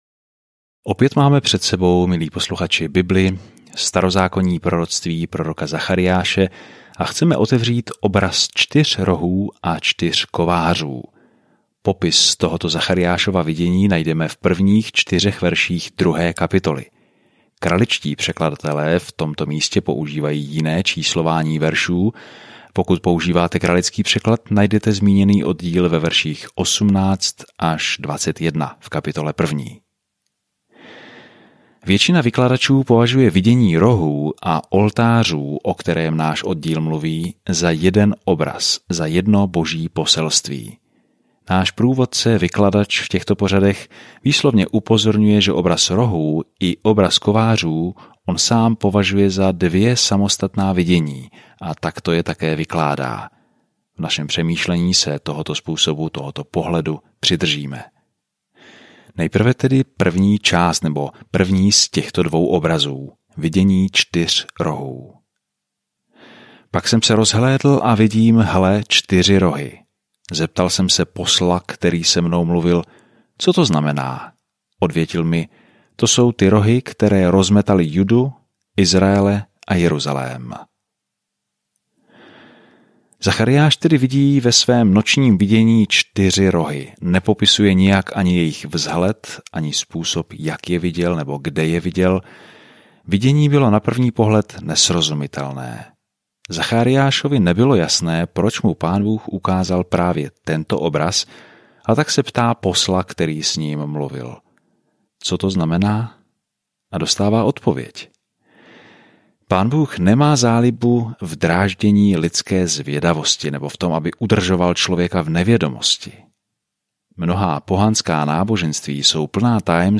Písmo Zachariáš 2:1-4 Den 3 Začít tento plán Den 5 O tomto plánu Prorok Zachariáš sdílí vize Božích zaslíbení, které lidem dají naději na budoucnost, a vybízí je, aby se vrátili k Bohu. Denně procházejte Zachariášem a poslouchejte audiostudii a čtěte vybrané verše z Božího slova.